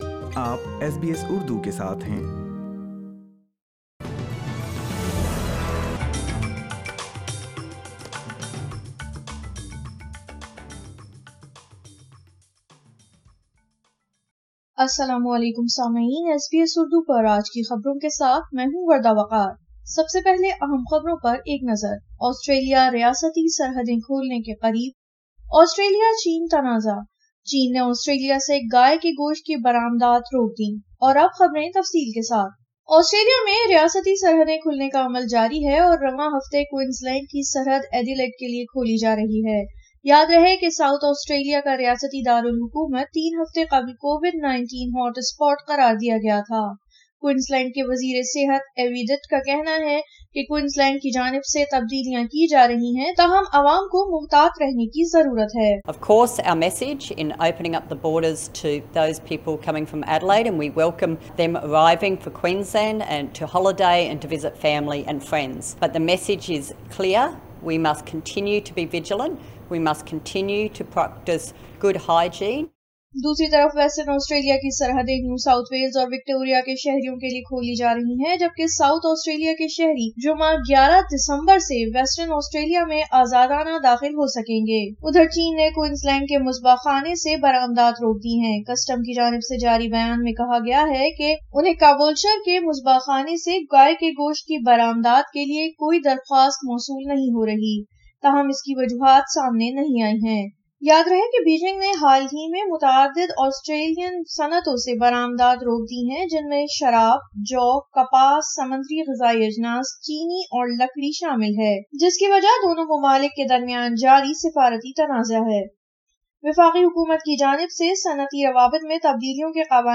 آسٹریلیا ریاستی سرحدیں کھولنے کے قریب ۔ سنئے اردو میں خبریں